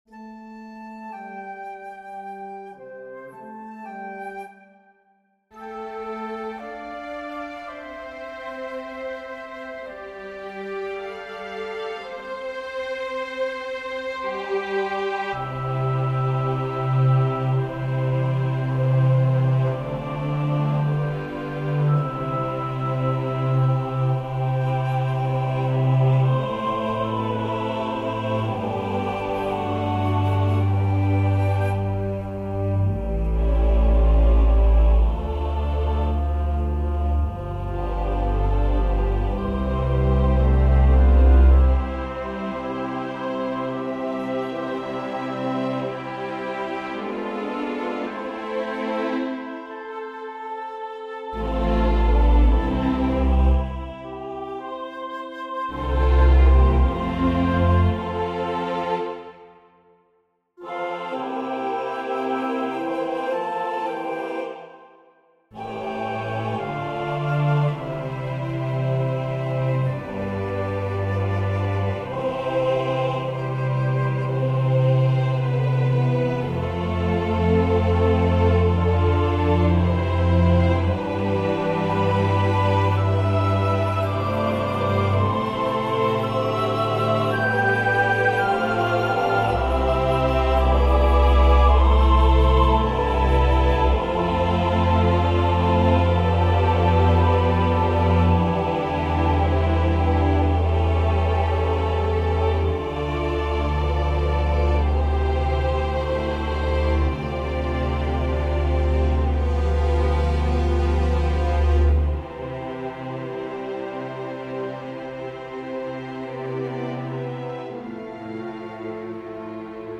Instrument Ensemble, Orchestra, SATB
Este es un arreglo de The Sevenfold Amen de Peter Lutkin para orquesta y coro. Se presenta aquí con un acompañamiento orquestal enriquecido que resalta la belleza de la pieza original para coro a cappella.